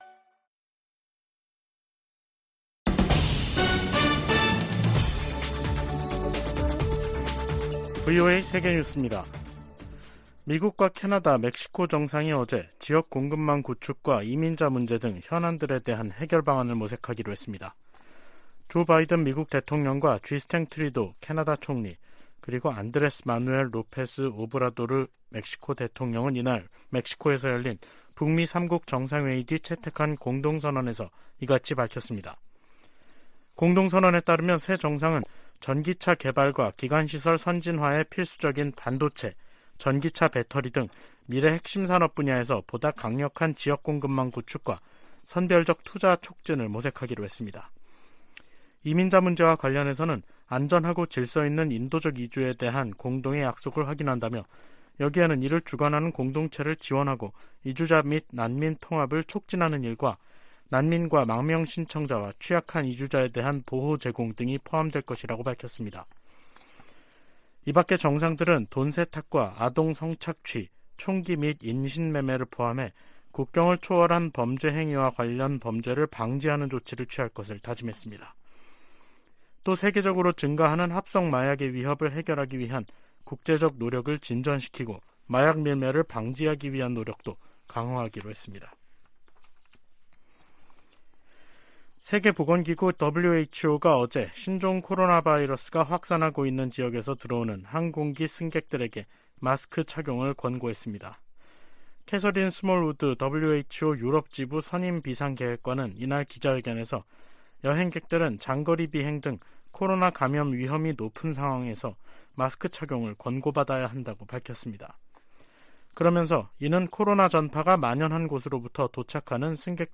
VOA 한국어 간판 뉴스 프로그램 '뉴스 투데이', 2023년 1월 11일 3부 방송입니다. 윤석열 한국 대통령은 북한의 잇단 도발 행위들은 한국의 대응 능력을 강화하고, 미한일 간 안보 협력을 강화하는 결과를 가져올 것이라고 말했습니다. 미국과 한국은 다음달 북한의 핵 공격 시나리오를 가정한 확장억제수단 운용연습을 실시합니다.